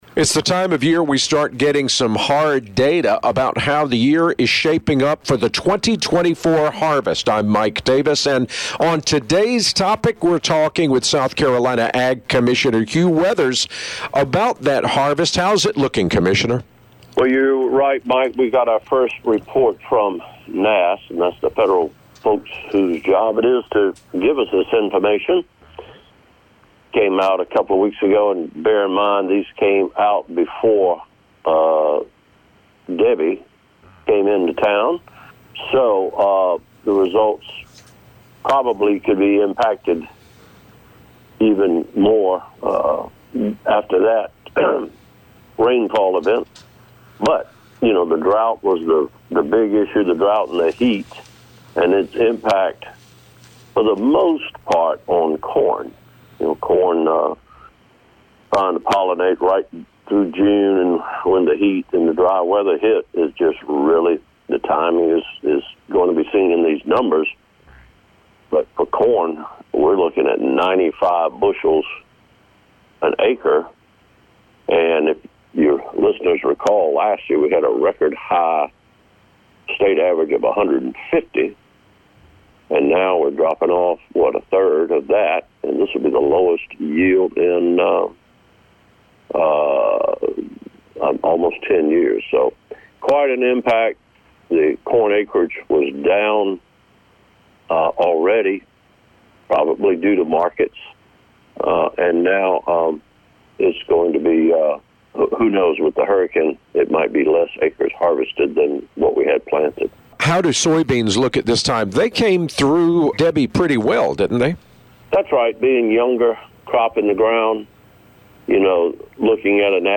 Every week Commissioner Hugh Weathers talks about what’s going on with agriculture in our state with The Southern Farm Network.